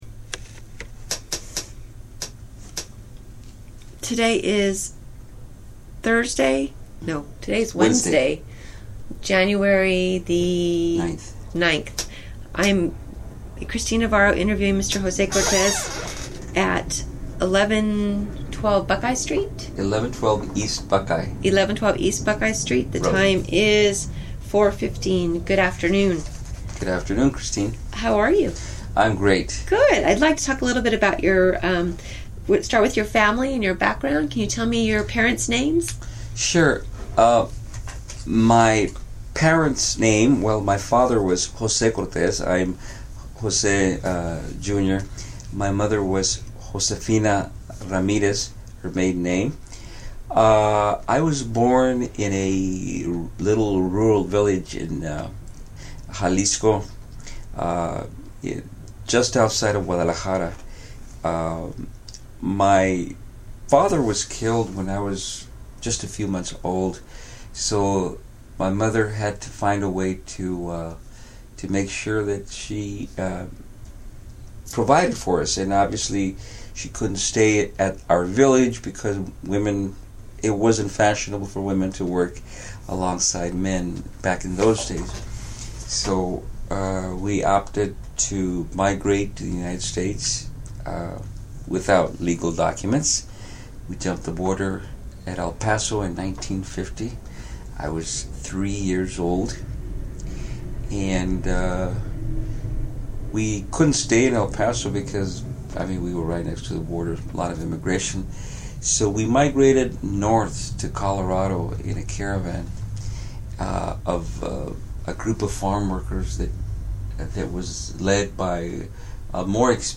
Location Phoenix, Arizona